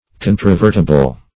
Search Result for " controvertible" : The Collaborative International Dictionary of English v.0.48: Controvertible \Con`tro*ver"ti*ble\, a. Capable of being controverted; disputable; admitting of question.